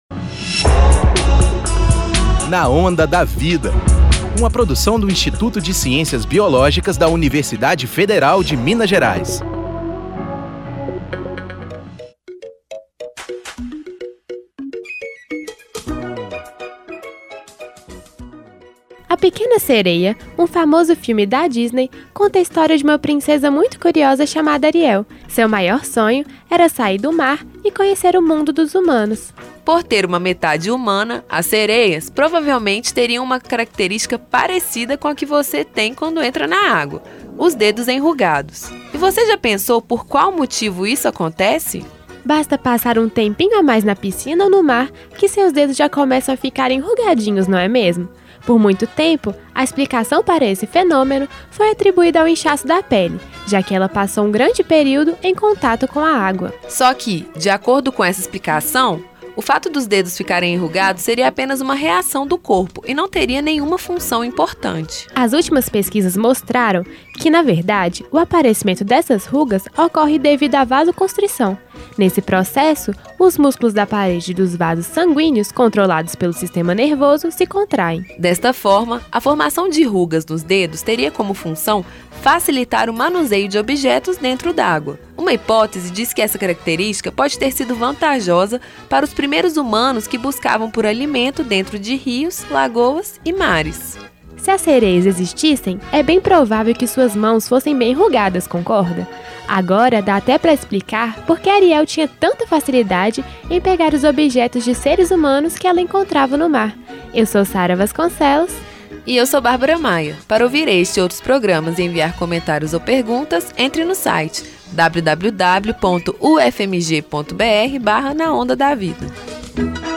Fala limpa e esclarecedora.